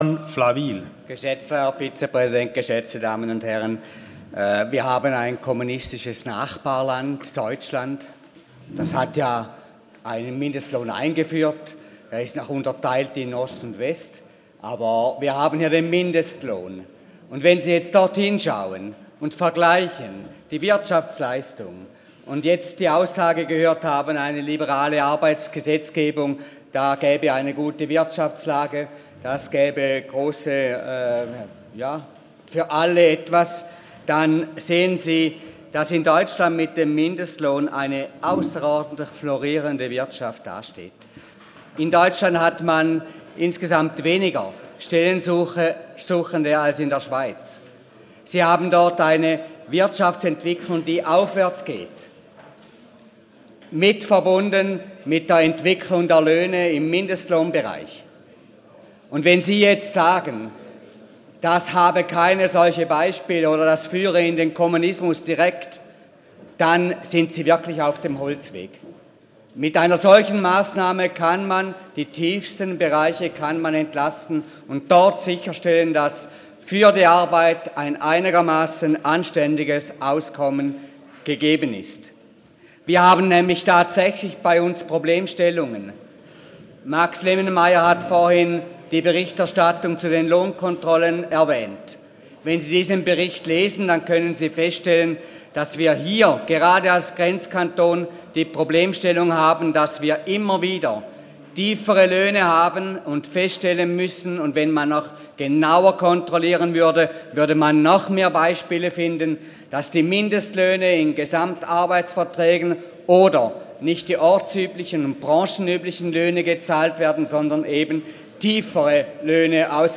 13.6.2018Wortmeldung
Session des Kantonsrates vom 11. bis 13. Juni 2018